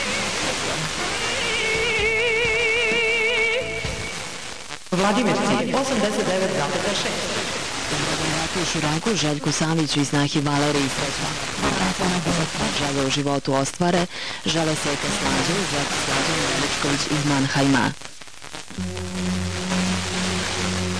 I heard this station here in Finland on August 2007 (